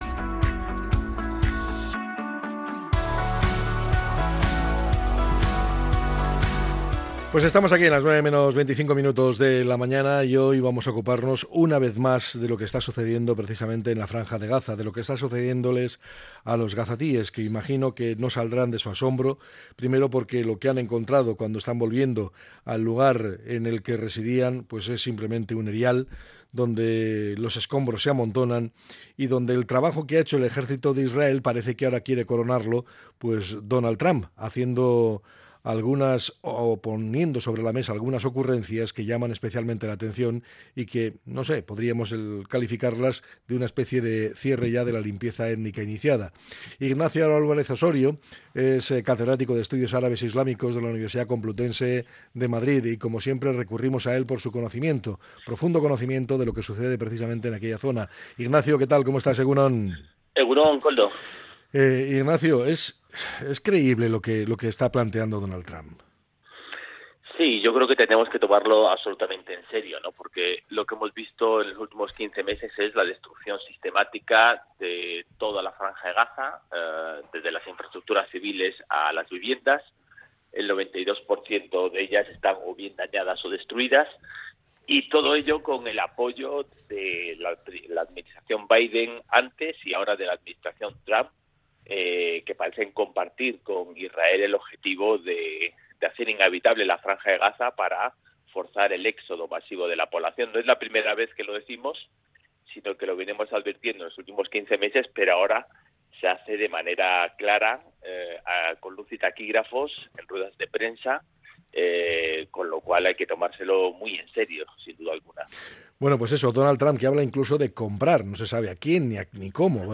Entrevista con el catedrático de Estudios Árabes e Islámicos